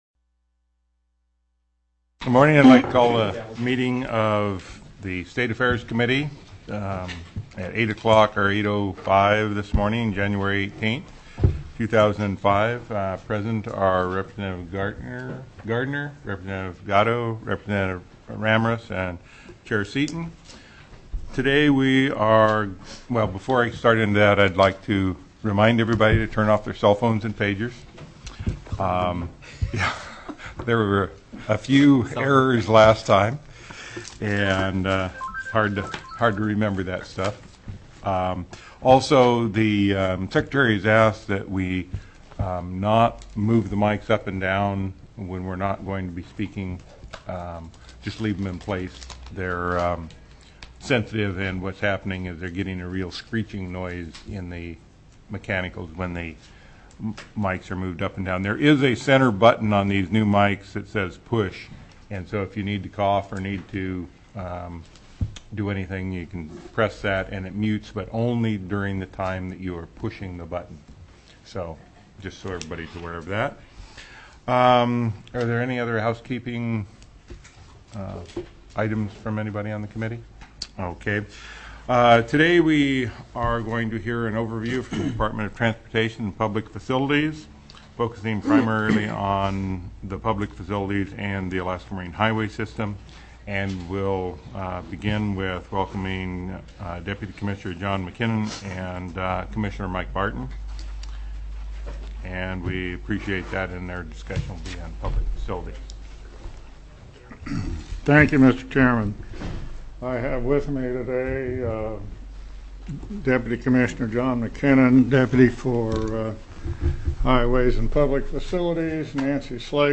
01/18/2005 08:00 AM House STATE AFFAIRS